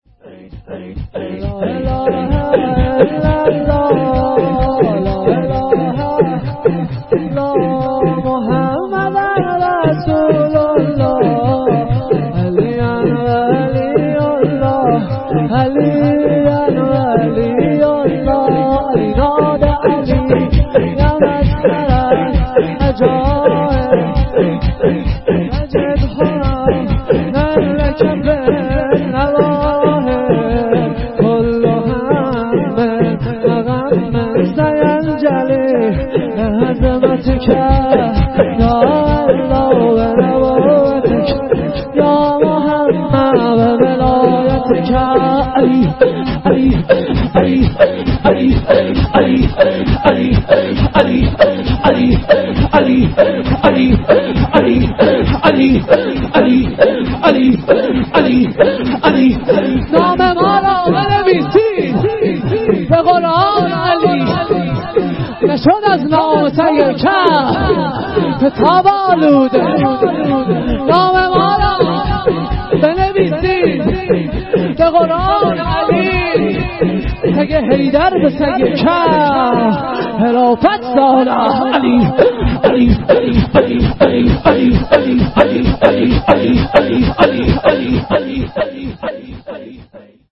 لا اله الا الله(شور....
جلسه هفتگی